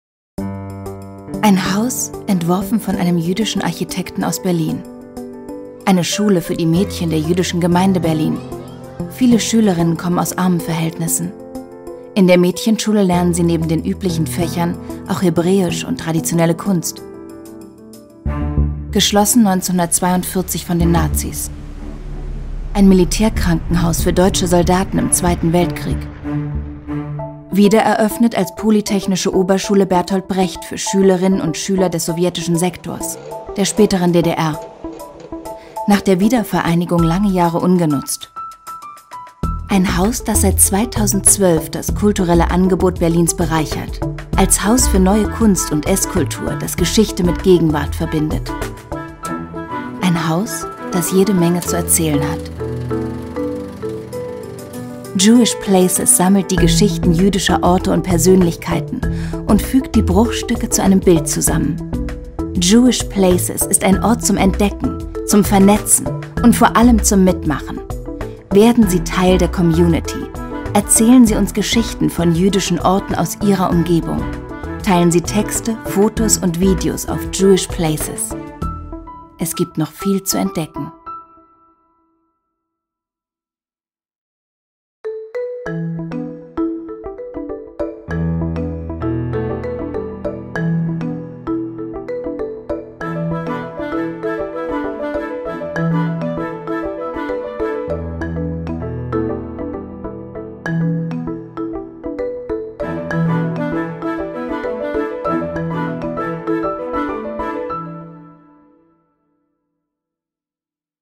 Vielseitige Sprecherin mit wandelbarer Stimme und eigenem Studio
Sprechprobe: Sonstiges (Muttersprache):